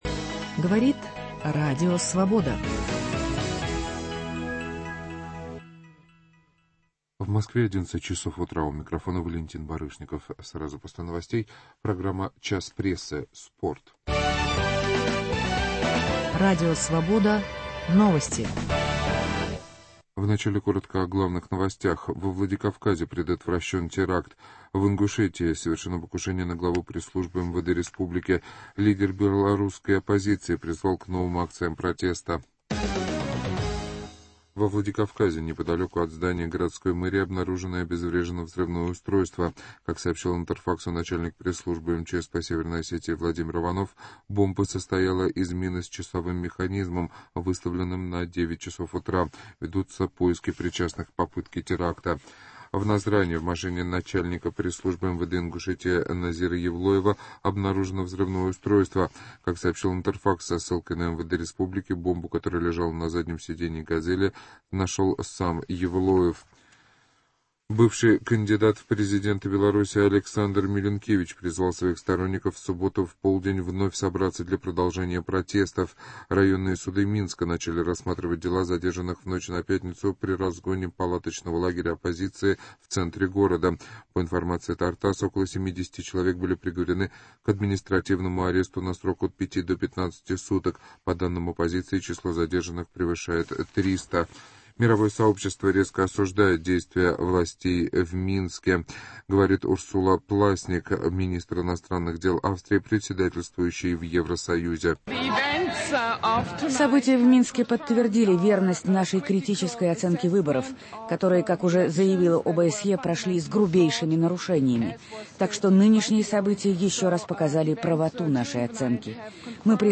По субботам - обсуждение самых интересных публикаций и телерепортажей; выступления компетентных экспертов; ответы на вопросы и мнения слушателей. Сегодня в программе - 85 лет шахматисту Василию Смыслову и футбольные чемпионаты.